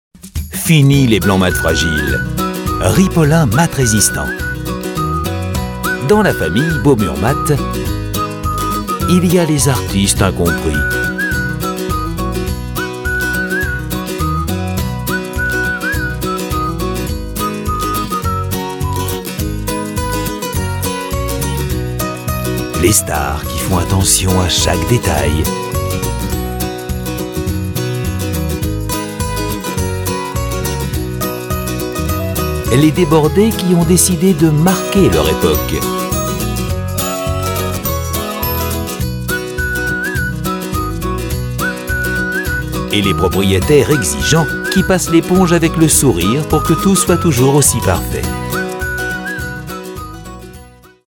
Un ton léger, une voix off naturelle et sympa dans ce Motion Design réalisé pour la marque Ripolin.
Voix off pour film de présentation produits